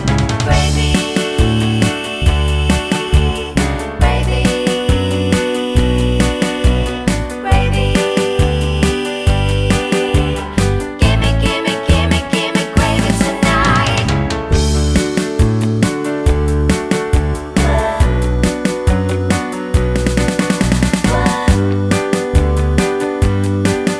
no sax solo Soundtracks 2:20 Buy £1.50